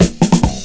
DRUMFILL07-R.wav